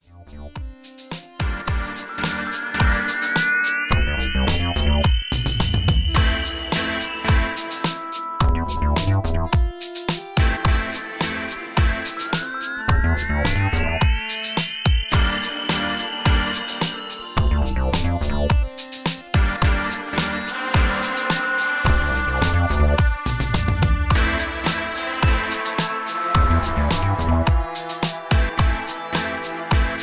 Instrumental Track/ Digital Single.